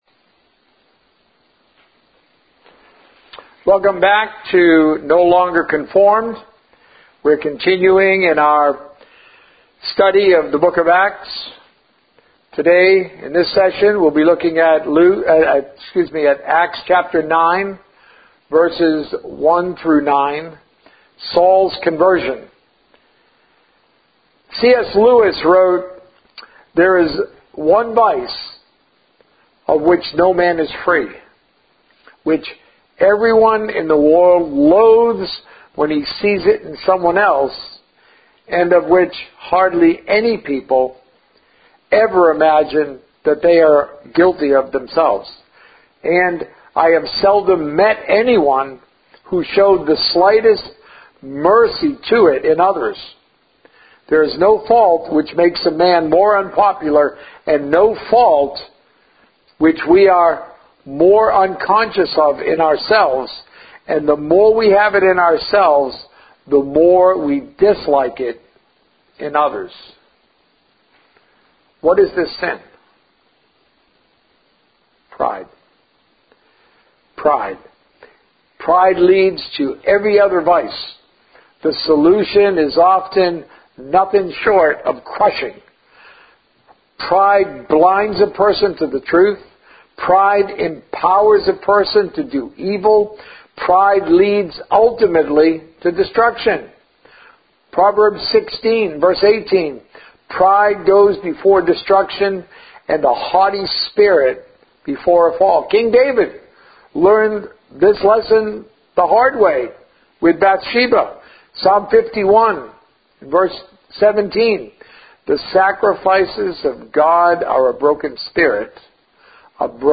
A message from the series "It's a New Day." A message encouraging debt-free living